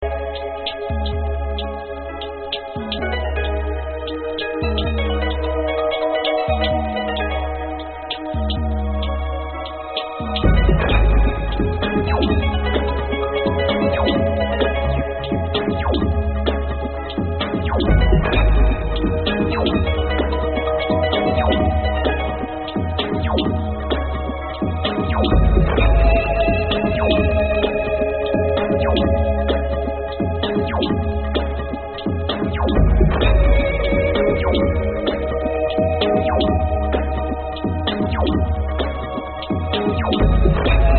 Unhappy some ambient track?